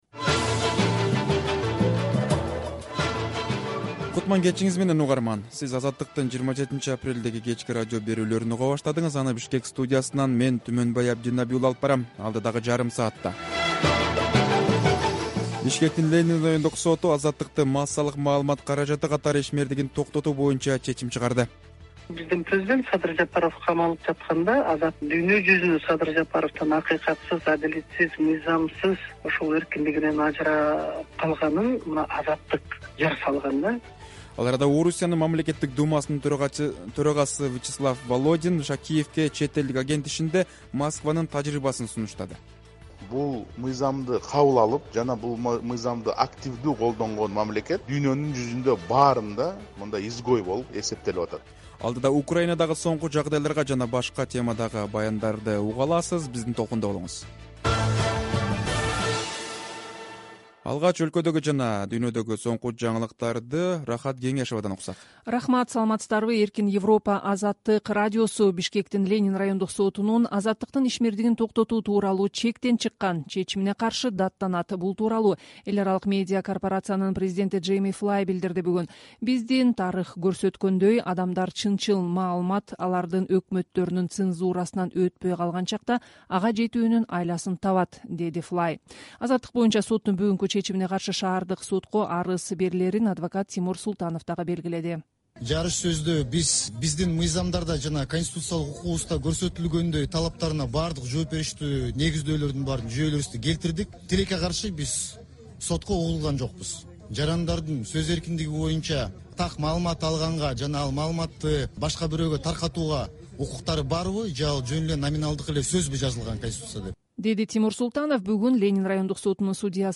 Кечки радио эфир | 27.04.2023 | Сот "Азаттык Медиа” мекемесин жабуу чечимин чыгарды